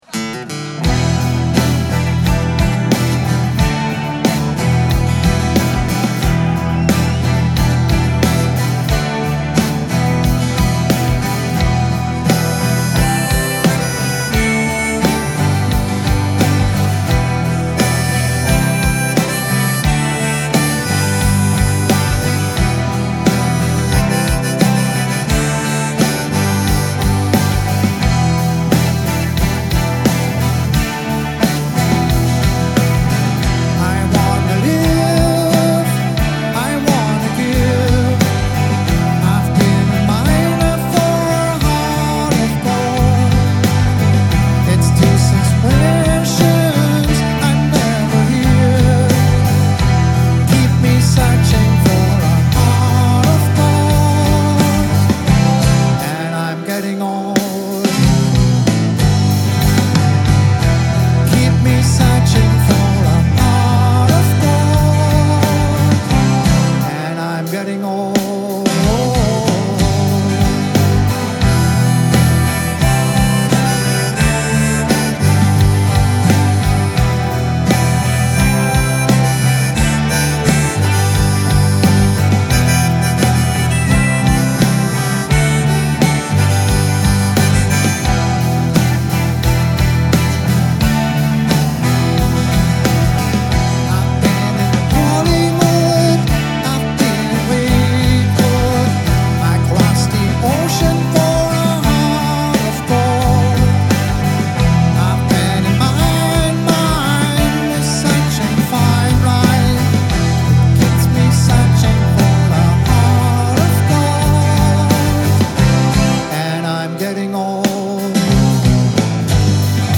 • Unplugged
• Rockband